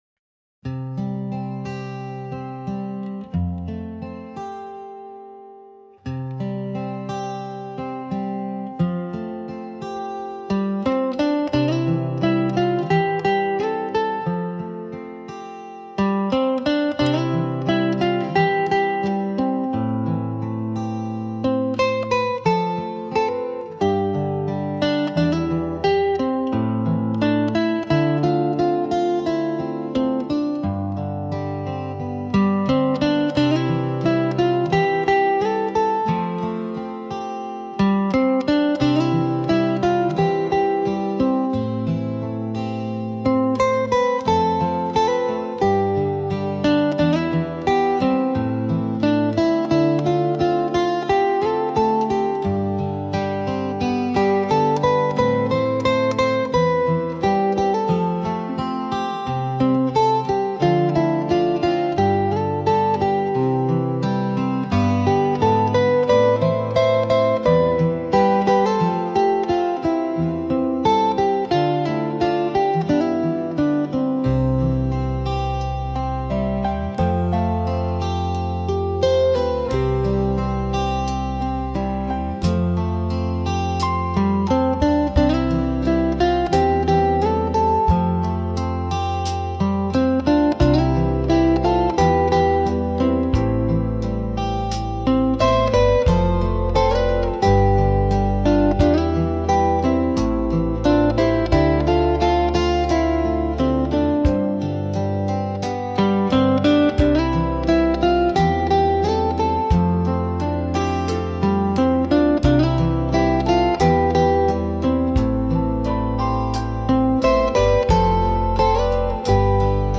心温まるアコースティックバラード・ボーカル無し
ポップス バラード アコースティック 明るい 優しい